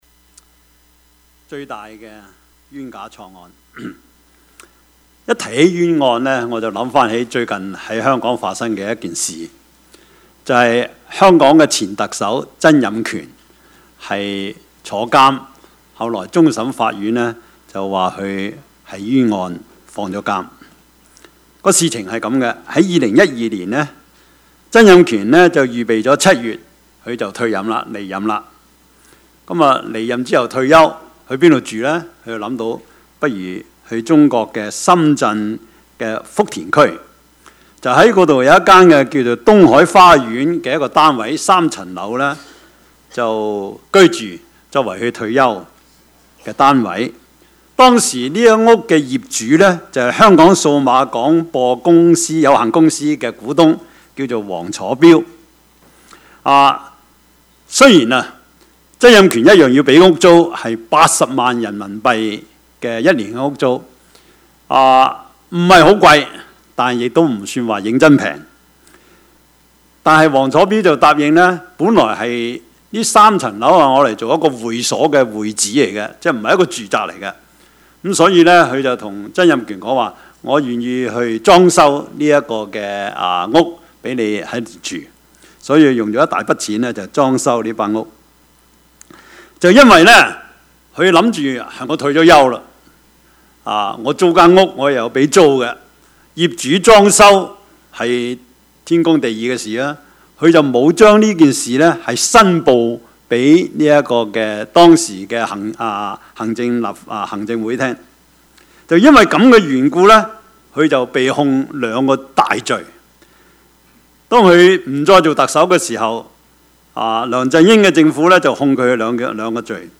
Service Type: 主日崇拜
Topics: 主日證道 « 開放的心 – 呂底亞 一家兩制 »